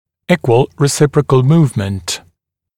[‘iːkwəl rɪ’sɪprəkl ‘muːvmənt] [‘и:куэл ри’сипрэкл ‘му:вмэнт] одинаковое взаимное перемещение